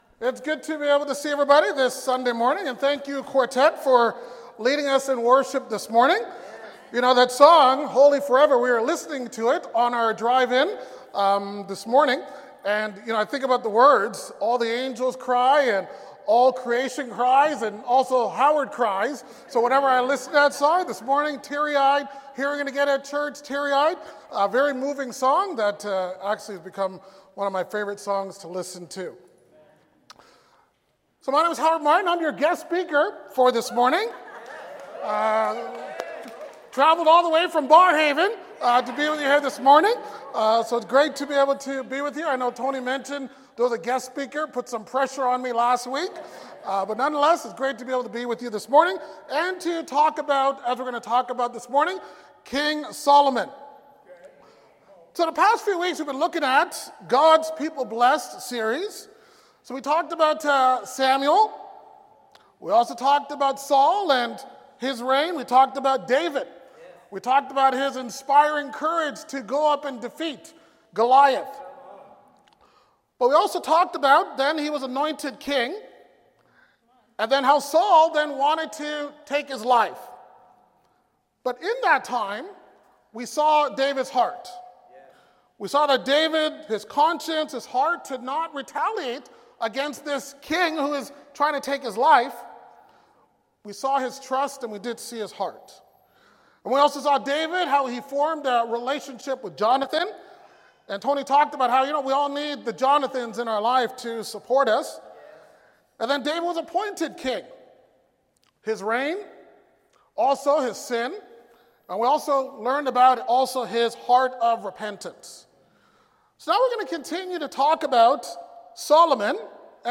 Ottawa Church Of Christ Sermon Podcast